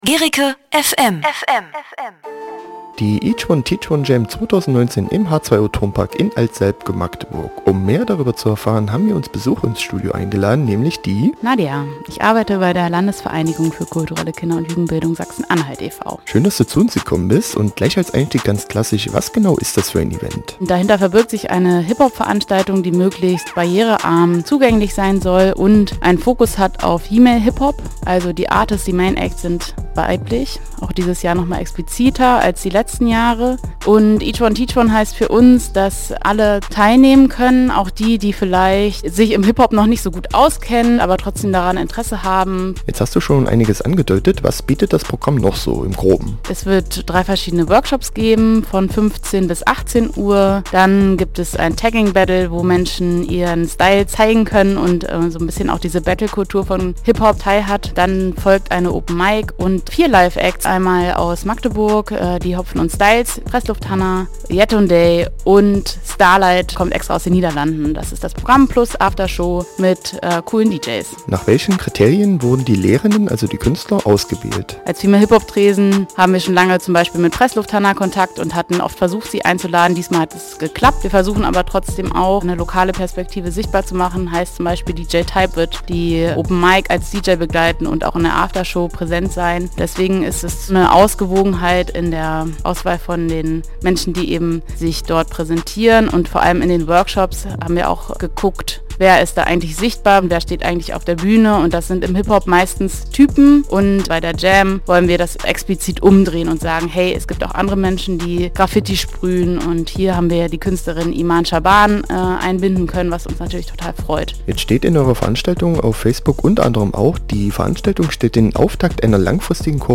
Inteview von Guericke FM